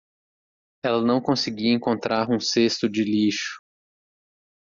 Pronounced as (IPA) /ˈses.tu/